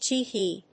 音節te‐hee 発音記号・読み方
/tiːhíː(米国英語)/